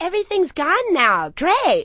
gutterball-3/Gutterball 3/Commentators/Poogie/everything_gone_great.wav at 0b195a0fc1bc0b06a64cabb10472d4088a39178a